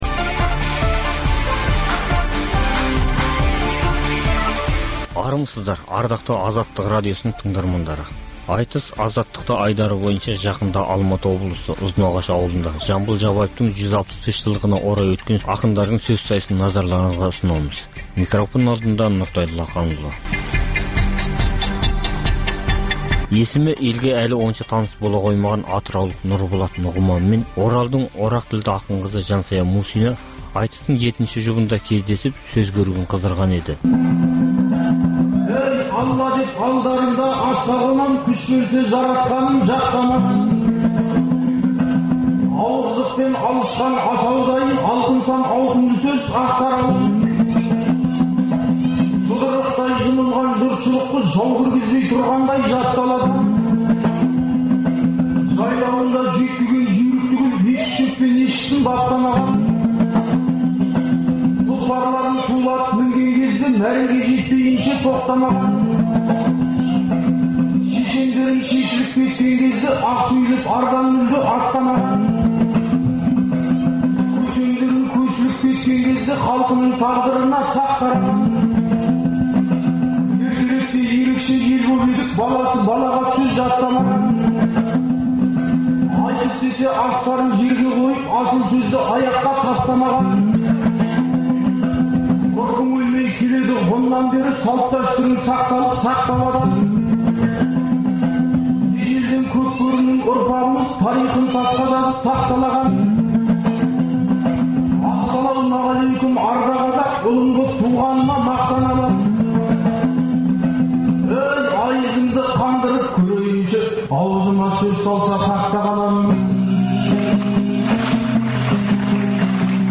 Дөңгелек үстел – Саяси және әлеуметтік саладағы күннің өзекті деген күйіп тұрған тақырыптарын қамту үшін саясаткерлермен, мамандармен, Қазақстаннан тыс жердегі сарапшылармен өткізілетін талқылау, талдау сұхбаты